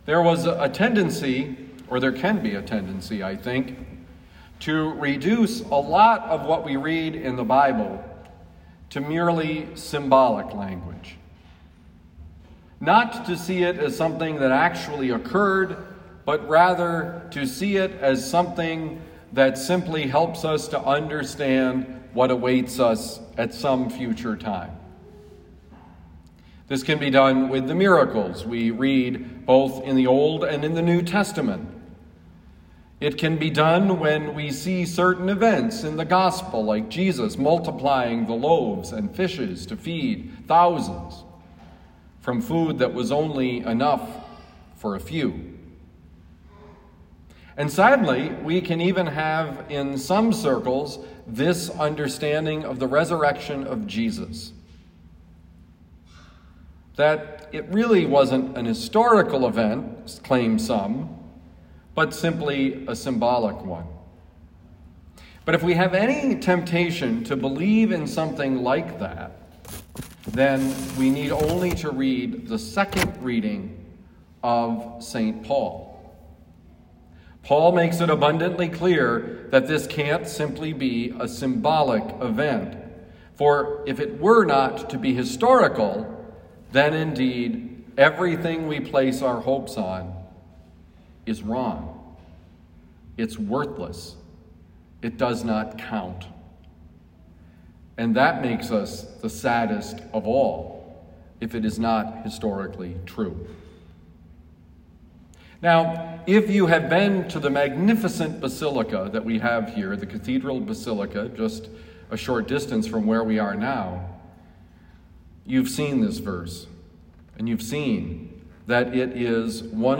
Homily given at Our Lady of Lourdes, University City, Missouri.